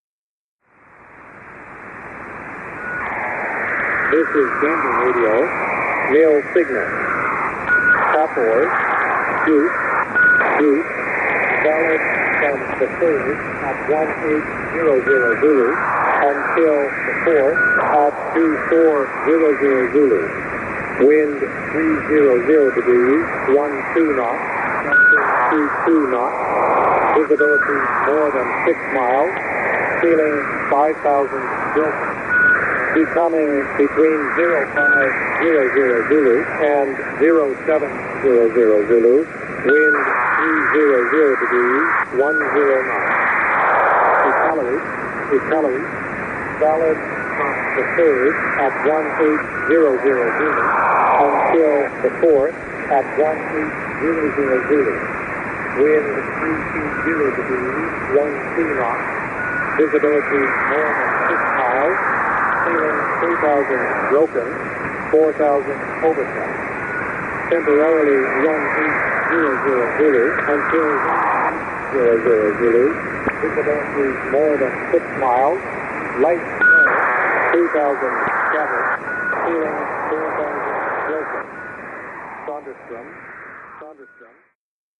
Because every time in the past I have heard HFDL on 13270kHz, I have also heard Gander Volmet on the same frequency.
The signals were received with an ICOM IC-R75 connected to an indoor Wellbrook 1530 loop antenna mounted in the attic.
I have wondered for years why I always hear both Gander Volmet and HatYai HFDL at the same time on this frequency, and never just one of them.